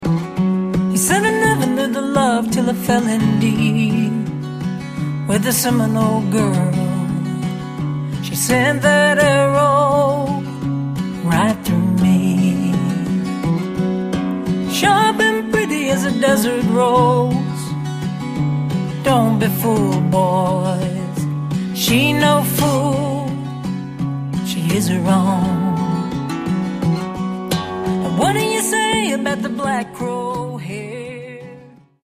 at Congress House Studios in Austin, Texas